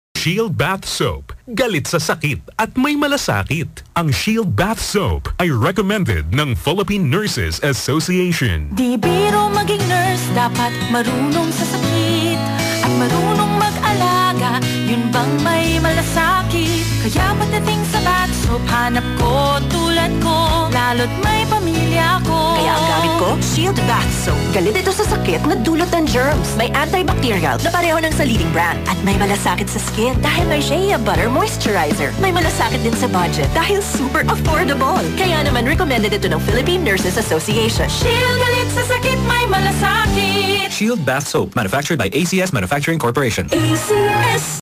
Shield Bath Soap Radio Commercial